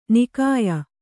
♪ nikāya